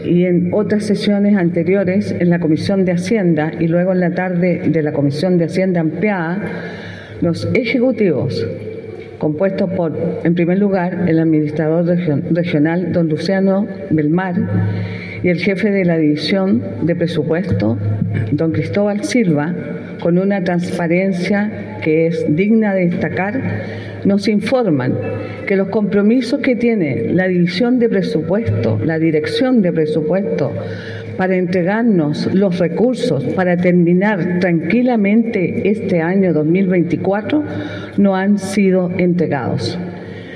María Angélica Barraza, Presidenta de la Comisión de Hacienda del Consejo Regional, explicó que esta situación fue presentada ante los consejeros, donde se evidenció esta problemática.